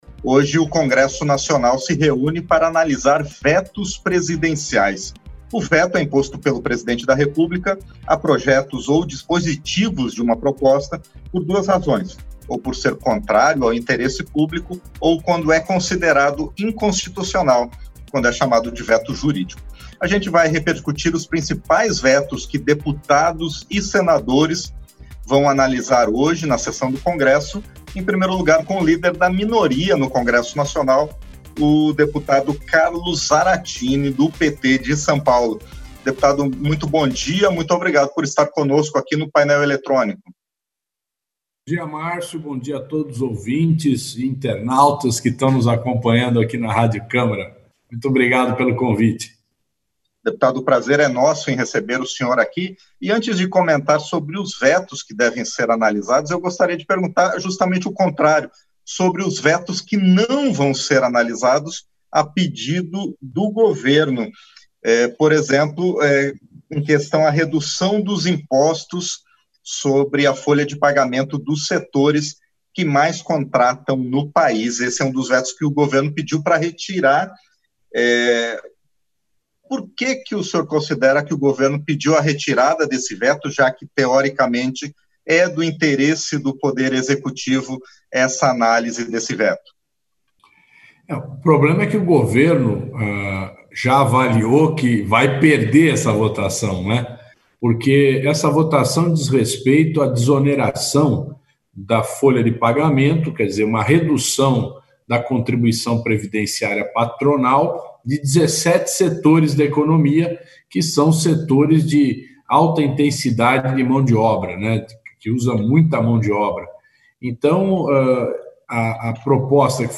Entrevista - Dep. Carlos Zarattini (PT-SP)